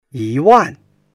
yi1wan4.mp3